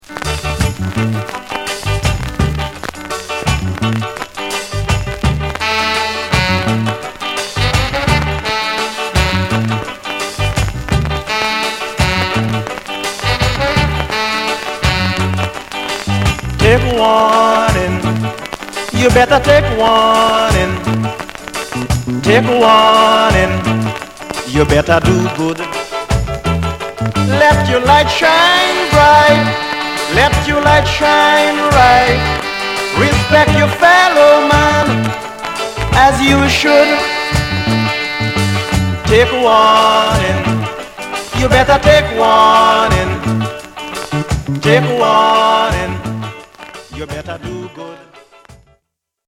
両面共に大きな傷有り、音に出るノイズ有りの為、特価プライスです。